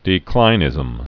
(dē-klīnĭz-əm)